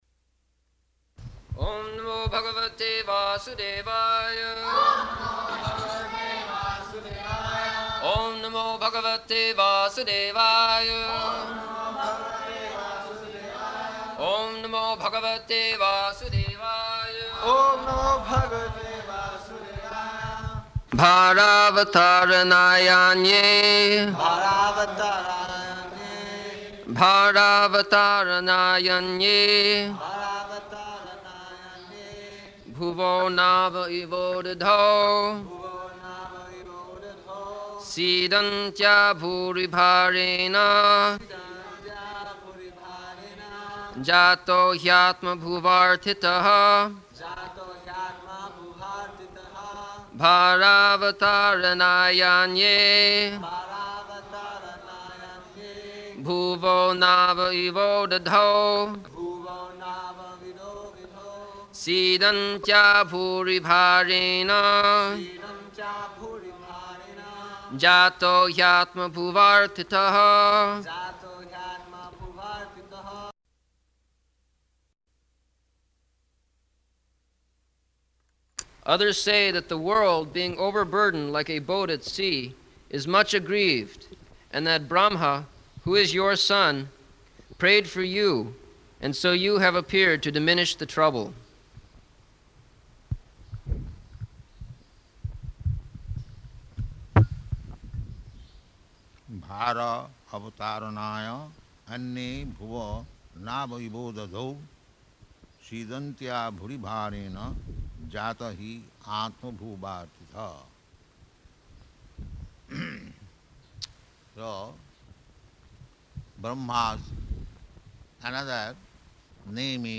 October 14th 1974 Location: Mayapur Audio file
[devotees repeat] [leads chanting of verse, etc.]